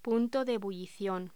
Locución: Punto de ebullición
voz
Sonidos: Voz humana